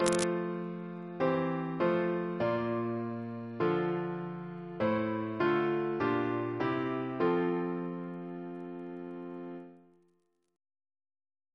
Single chant in F Composer: Robert Burton (1820-1892) Reference psalters: ACB: 261